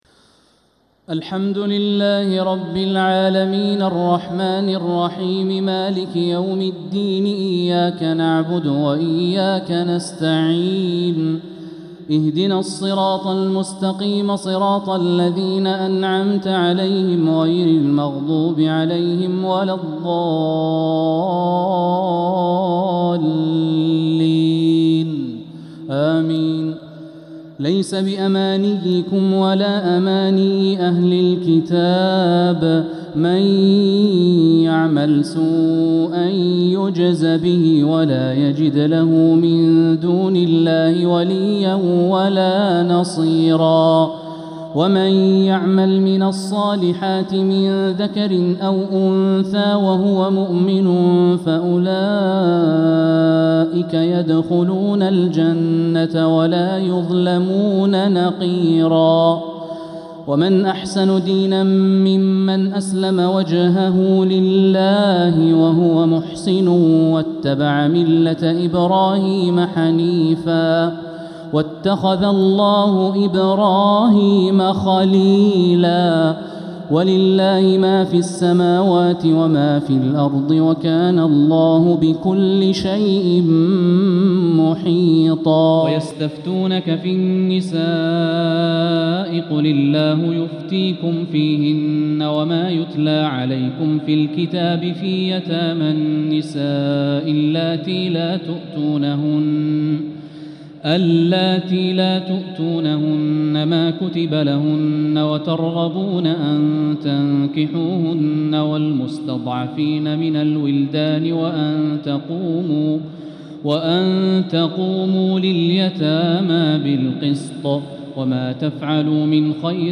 تراويح ليلة 7 رمضان 1447هـ من سورة النساء {123-162} Taraweeh 7th night Ramadan 1447H Surat An-Nisaa > تراويح الحرم المكي عام 1447 🕋 > التراويح - تلاوات الحرمين